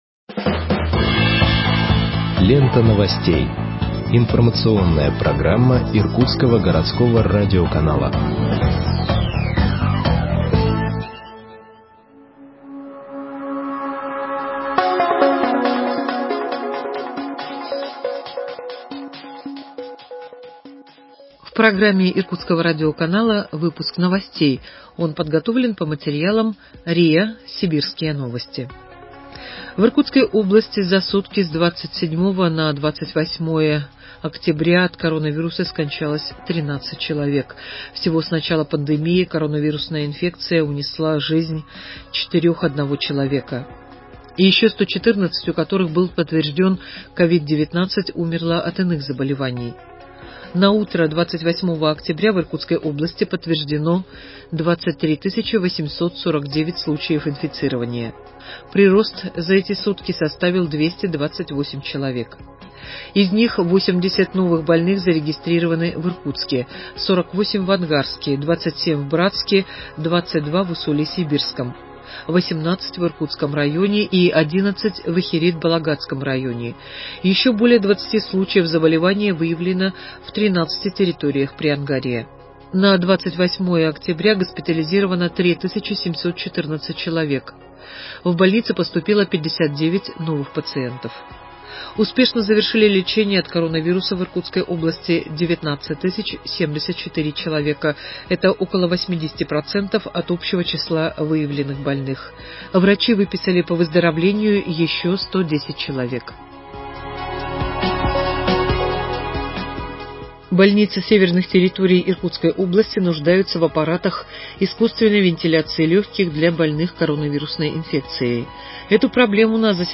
Выпуск новостей в подкастах газеты Иркутск от 29.10.2020 № 2